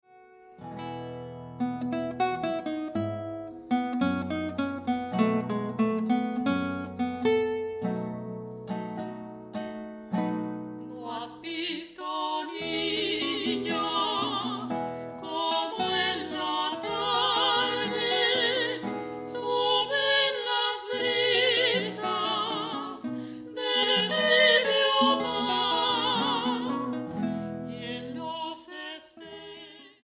Grabado en la Sala Julián Carrillo de Radio UNAM